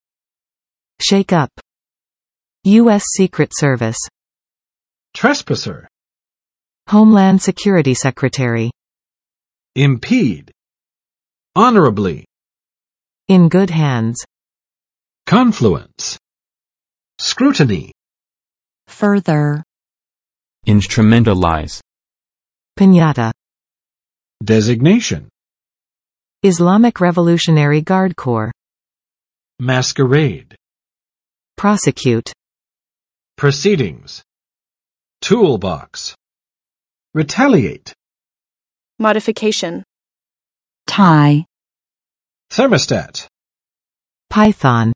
Vocabulary Test - April 9, 2019
[ˋtrɛspəsɚ] n. 侵害者；违反者；侵入者
[ɪmˋpid] v. 妨碍，阻碍；阻止
[ˋkɑnflʊəns] n.（人或物的）汇合，聚集